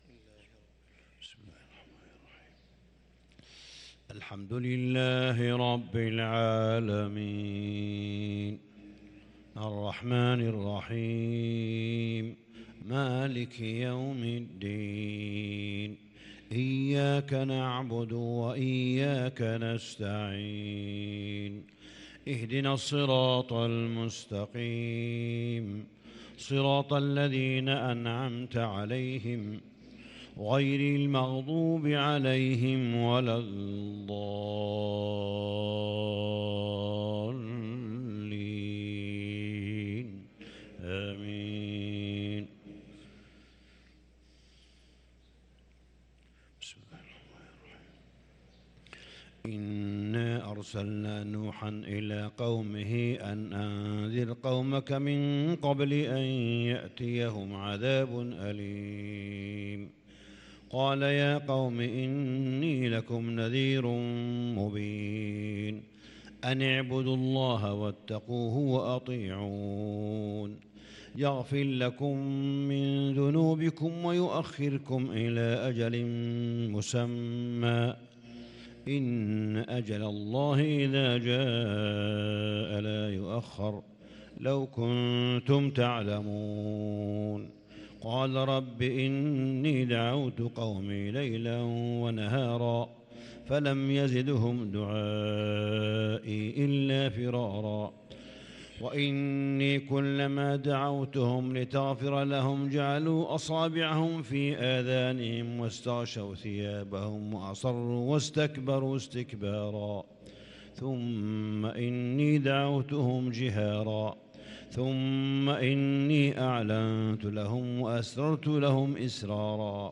فجر الأحد 2 رمضان 1443هـ سورة نوح كاملة | Fajr prayer from surat Nuh 3-4-2022 > 1443 🕋 > الفروض - تلاوات الحرمين